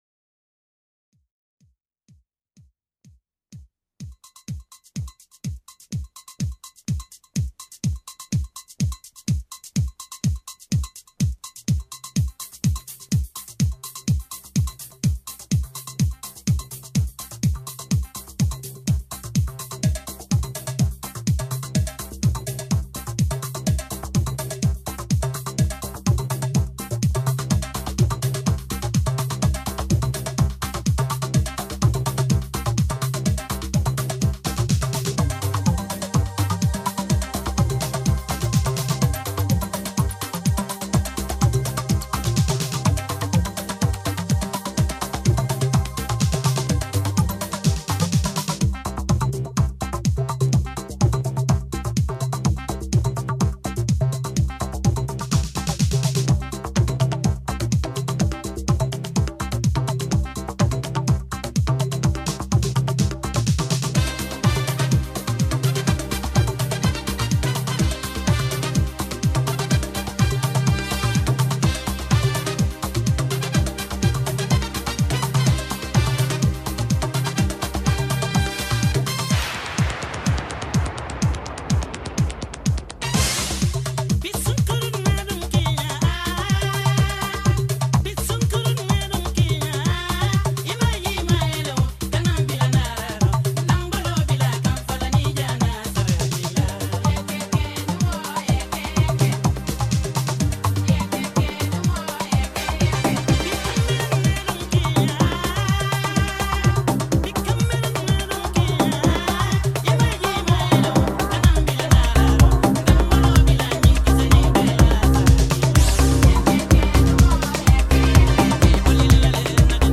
The last 15 minutes of dancefloor insanity